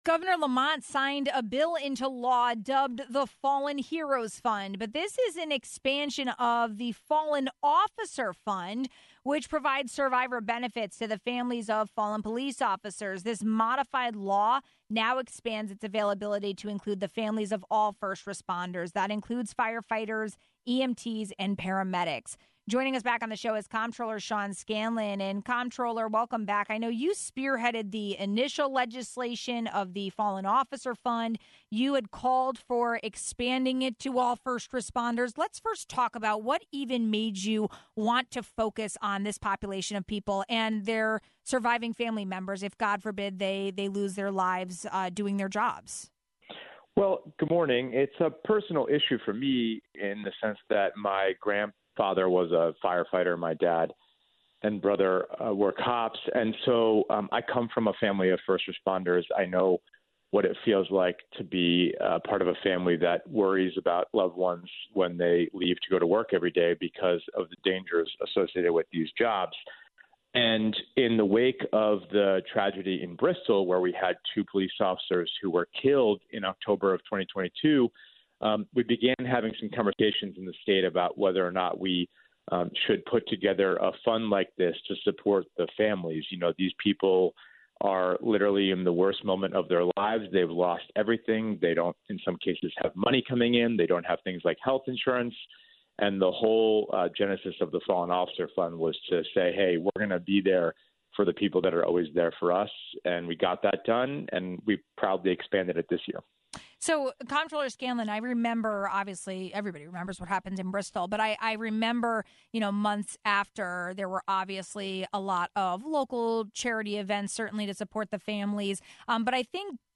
This modified law now expands its availability to include the families of all first responders, including firefighters, EMTs, and paramedics. Comptroller Sean Scanlon spearheaded the legislation and talked about the impact of the expansion now.